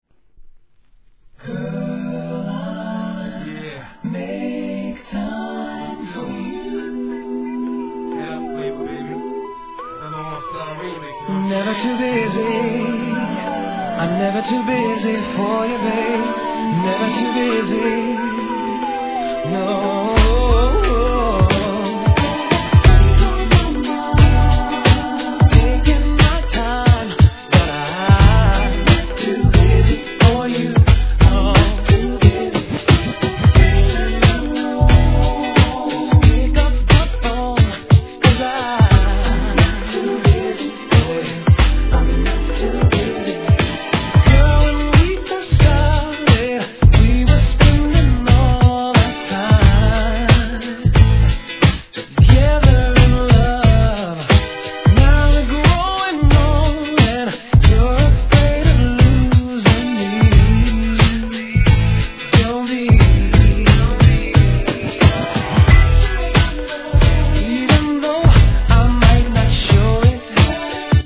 HIP HOP/R&B
その実力ある歌唱力は定評あり!